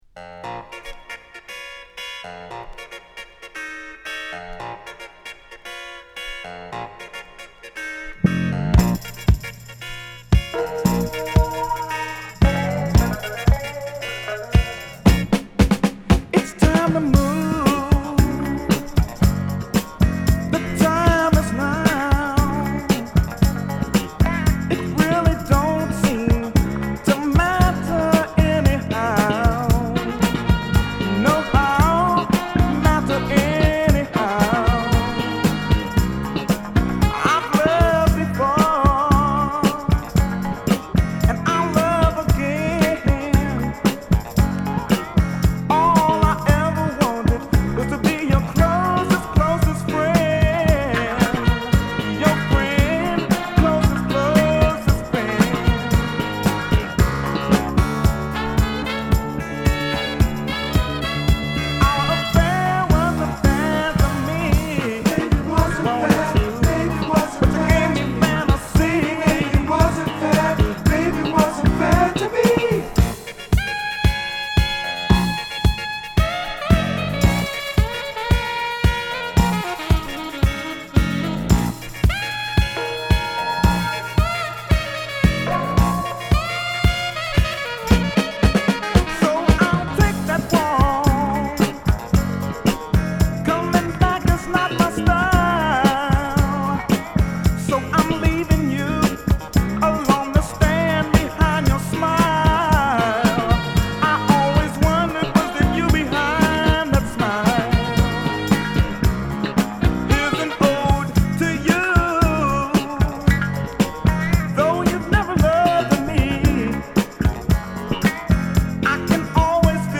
アルバム通してクオリティ高いメロウ／モダン・ソウル／ディスコ／ブギーを収録！